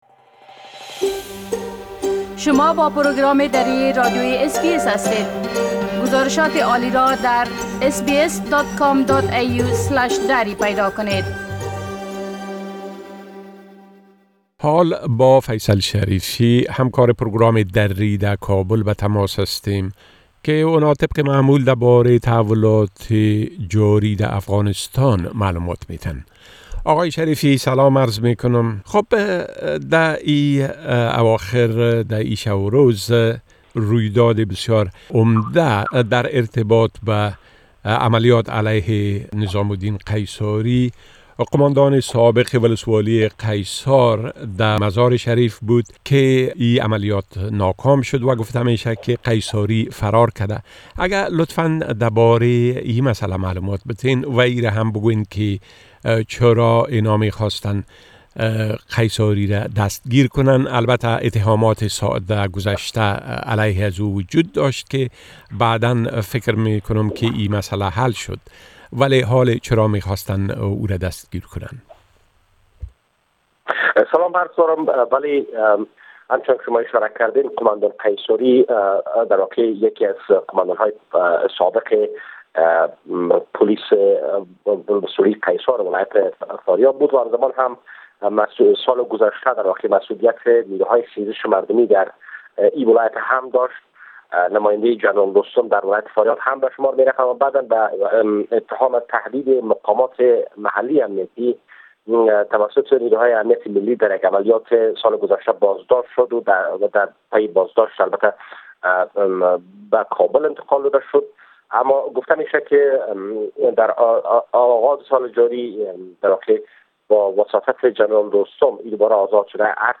گرازش كامل خبرنگار ما در كابل بشمول ادامۀ بازشمارى آراى انتخابات رياست جمهورى و تحولات مهم ديگر در افغانستان را در اينجا شنيده ميتوانيد.